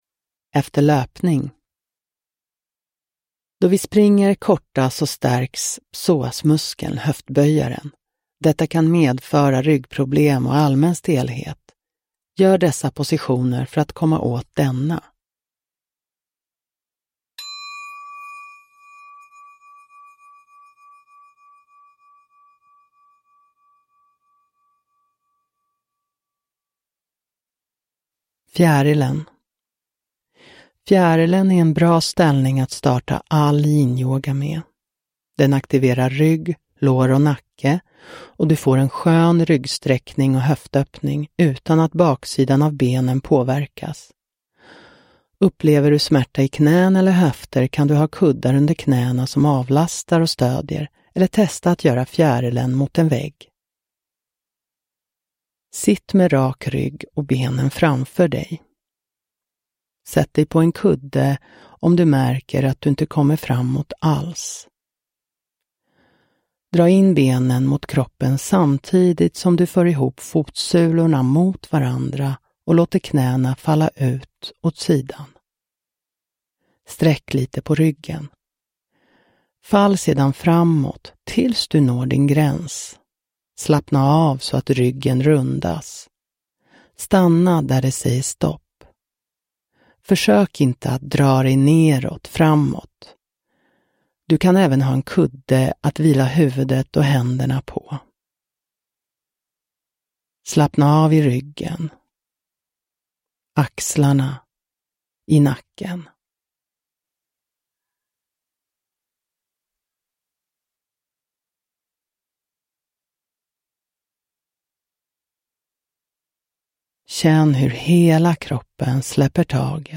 Efter löpning – Ljudbok – Laddas ner